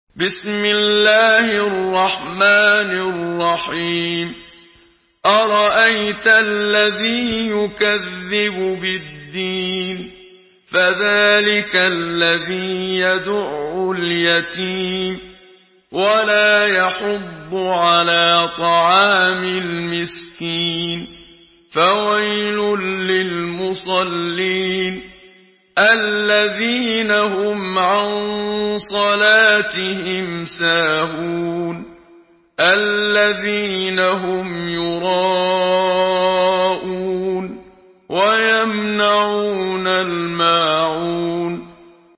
Surah Al-Maun MP3 by Muhammad Siddiq Minshawi in Hafs An Asim narration.
Murattal Hafs An Asim